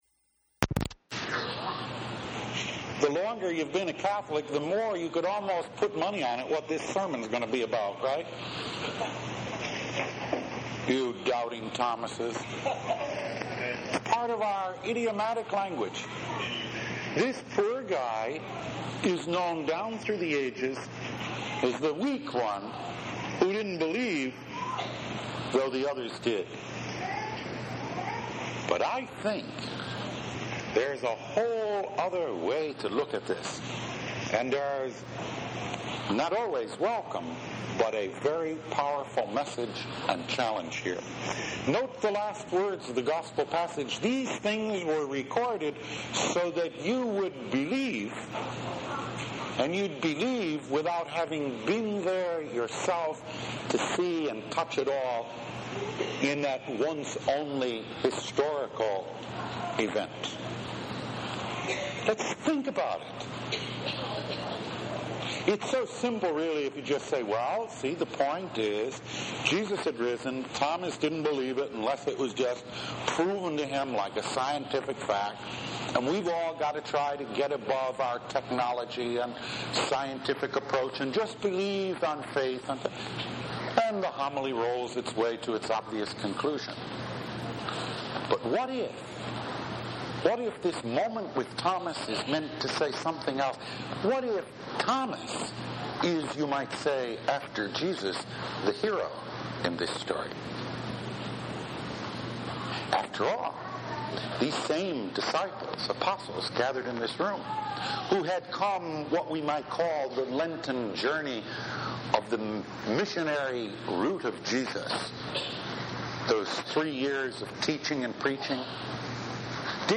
Jesus’s Humanity – Weekly Homilies